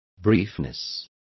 Complete with pronunciation of the translation of briefness.